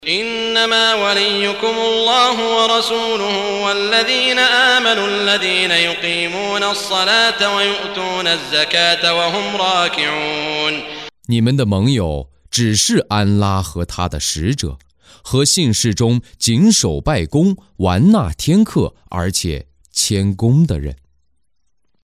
قراءة صوتية باللغة الصينية لمعاني سورة المائدة مقسمة بالآيات، مصحوبة بتلاوة القارئ سعود الشريم.